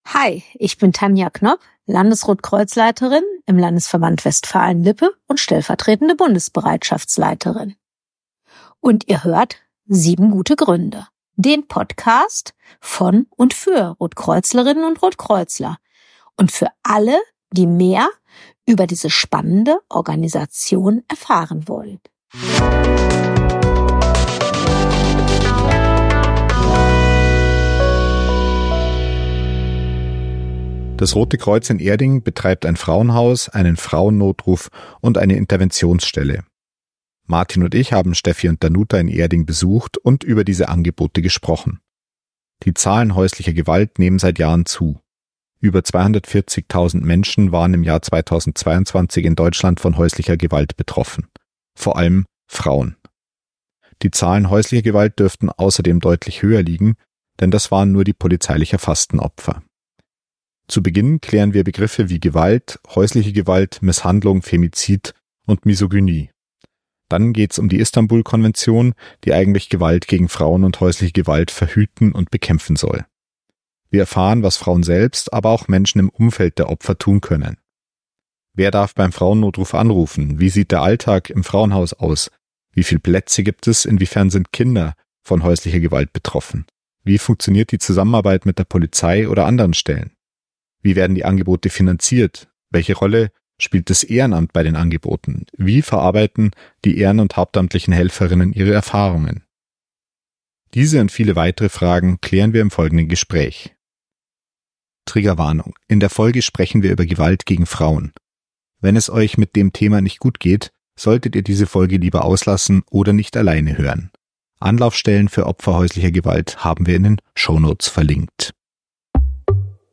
Diese und viele weitere Fragen klären wir im Gespräch.